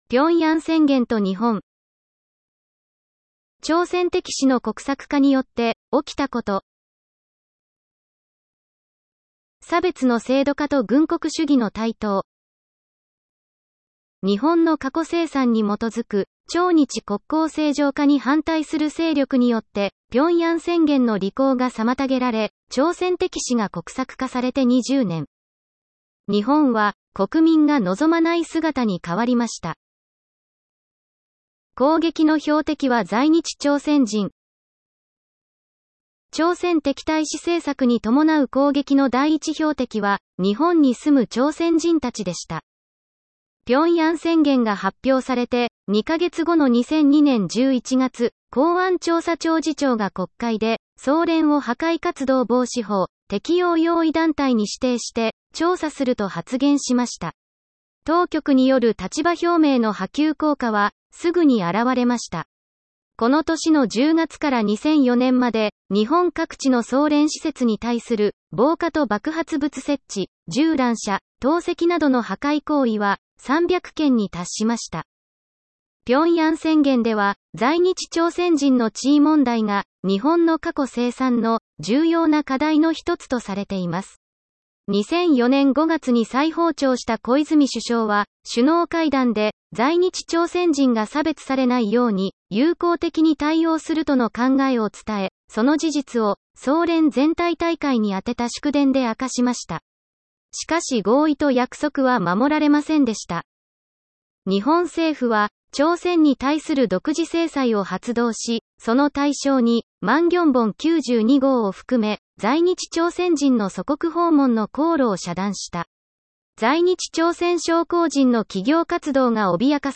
「聴くシンボ」は、朝鮮新報電子版 DIGITAL SINBOのニュースを音声でお聞きいただけます。